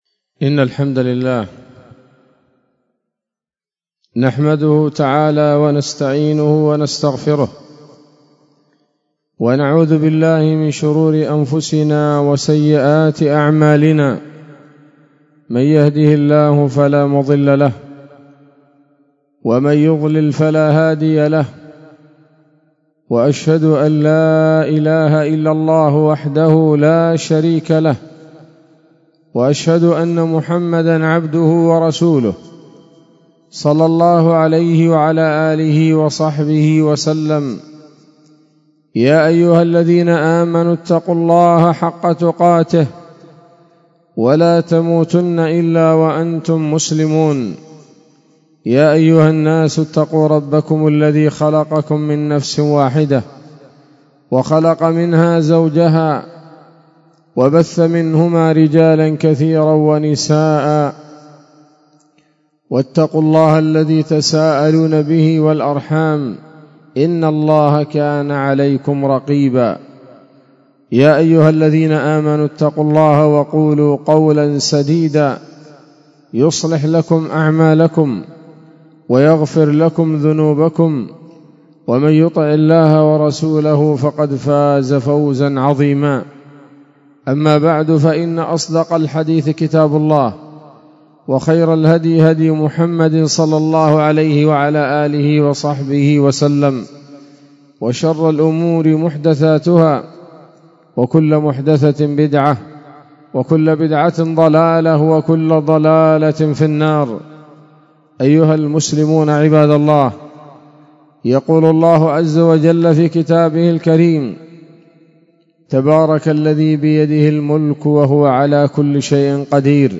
خطبة جمعة بعنوان: (( عمرك في الدنيا قصير )) 3 شوال 1445 هـ، دار الحديث السلفية بصلاح الدين